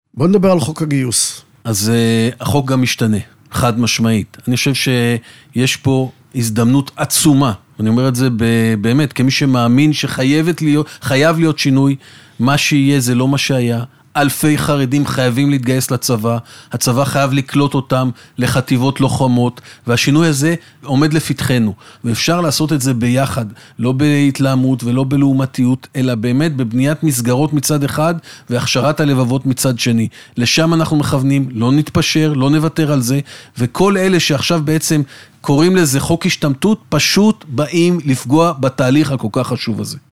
השר קיש בריאיון בתוכנית "הנבחרים" ברדיוס 100FM